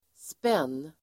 Uttal: [spen:]